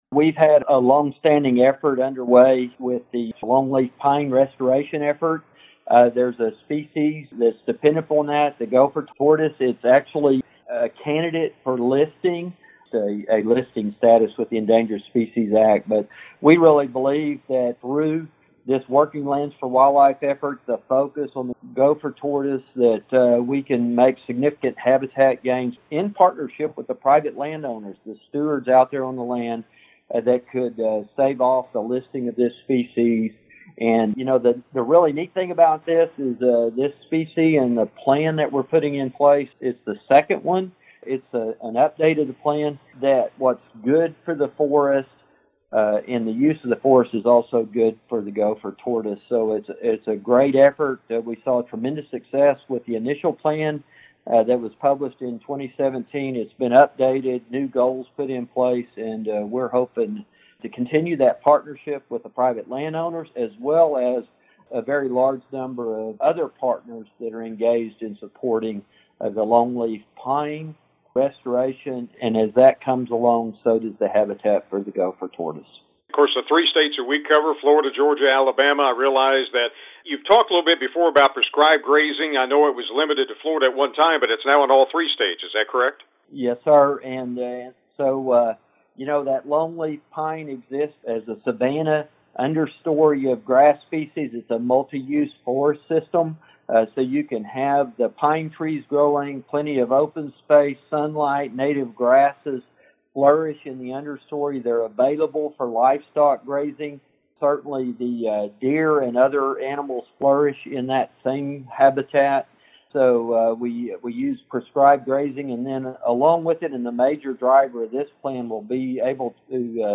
The U.S. Department of Agriculture’s (USDA) Natural Resources Conservation Service (NRCS) has released its new 5-year plan to conserve the Southeast’s threatened gopher tortoise by focusing on the conservation and restoration of its key habitat, the longleaf pine forests. Acting NRCS Chief Kevin Norton told Southeast AgNet the fate of the gopher tortoise is linked to habitat quality, and efforts to conserve habitat on private lands will be critical to its continued survival.